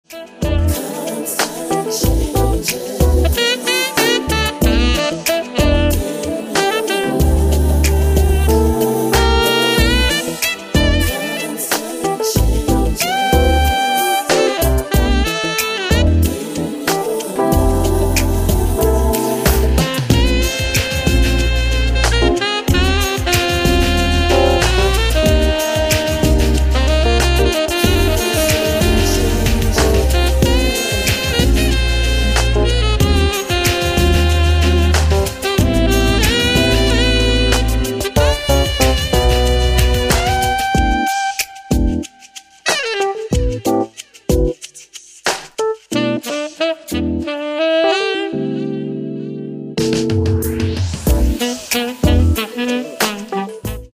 Saxophon & coole Sounds
Tenor-Saxophon